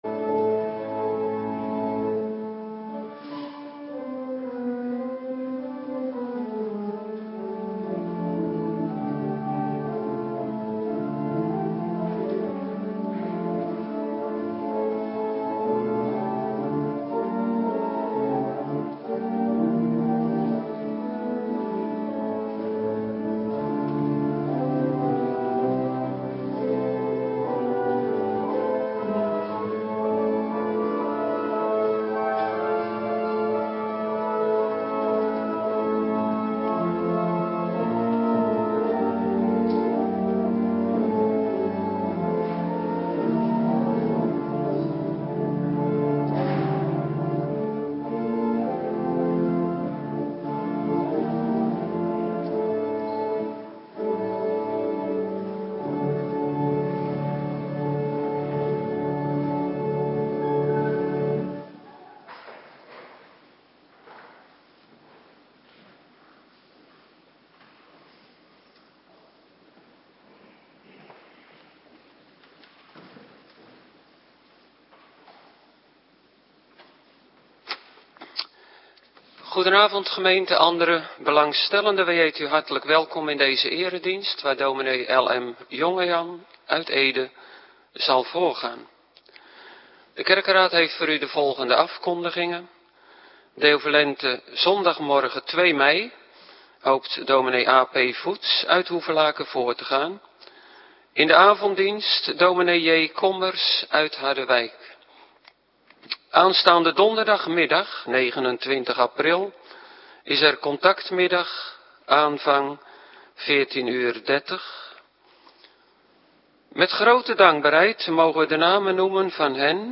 Avonddienst - Cluster 1
Locatie: Hervormde Gemeente Waarder